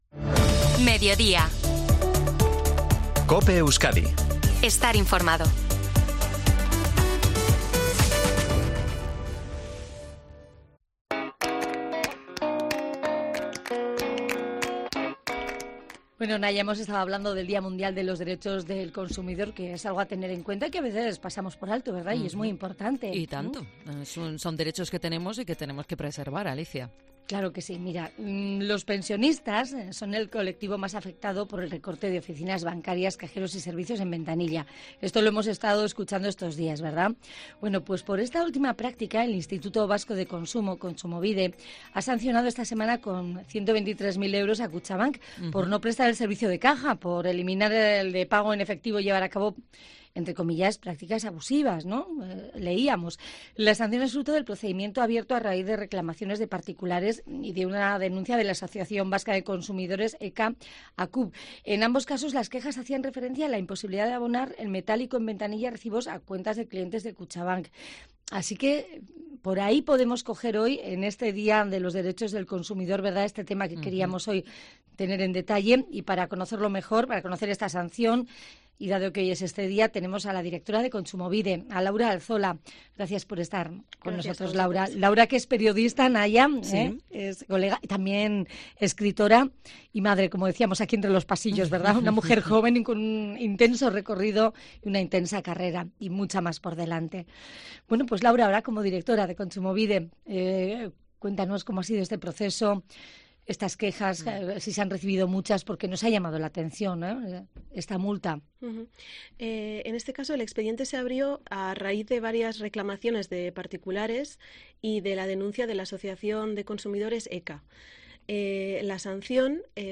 Entrevista Mediodía COPE Euskadi a Laura Alzola, directora de Kontsumobide